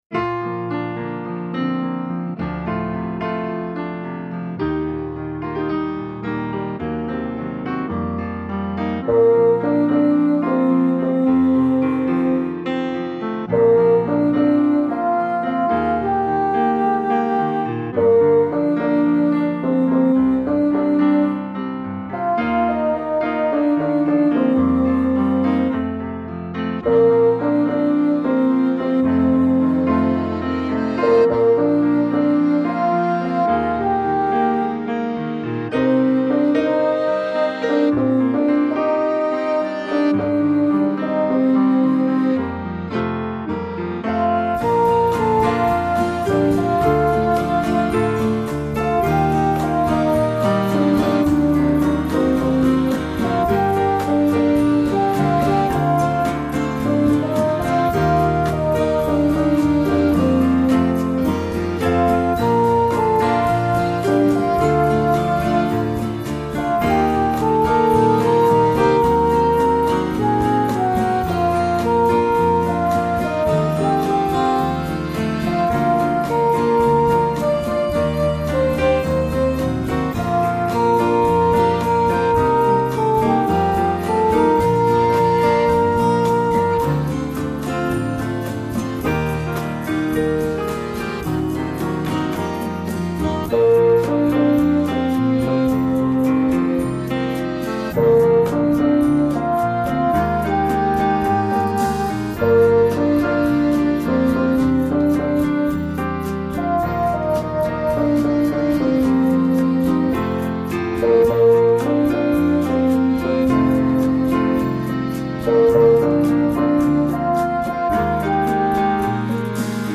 My backing tries to avoid that: